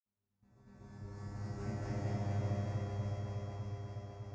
A segment of the "Salt Pour" audio file with an added audio effect called 'harmonics' in the delay section in Ableton Live. This sound is correlated with the letter "a" on the computer keyboard.